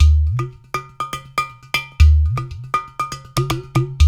120 -UDU 0DL.wav